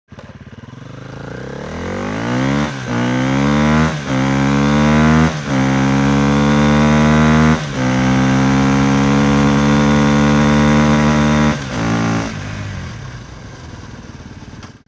دانلود آهنگ موتور 4 از افکت صوتی حمل و نقل
جلوه های صوتی
دانلود صدای موتور 4 از ساعد نیوز با لینک مستقیم و کیفیت بالا